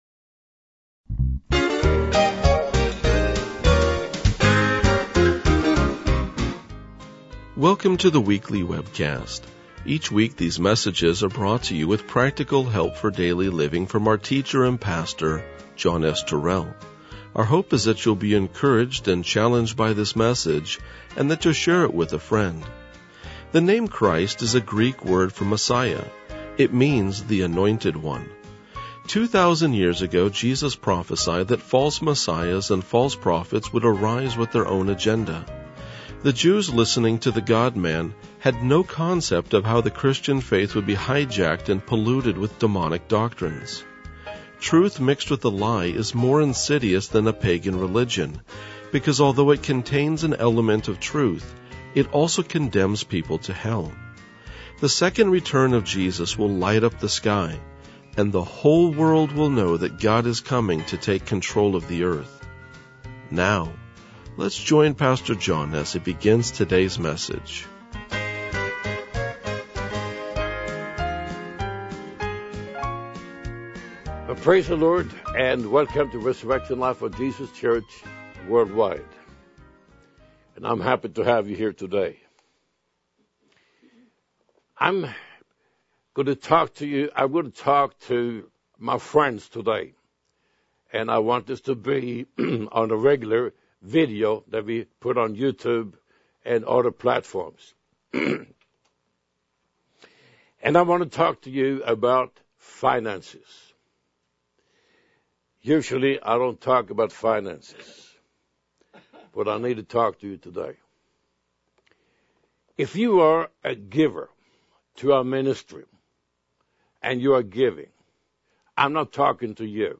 RLJ-1967-Sermon.mp3